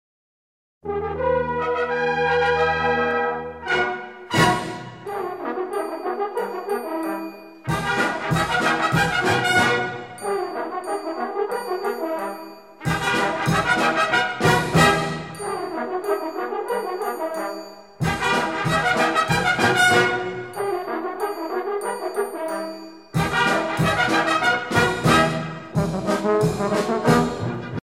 circonstance : militaire
Pièce musicale éditée